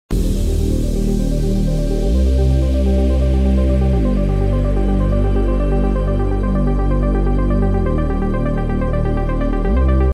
Jet above the clouds ☁✨ sound effects free download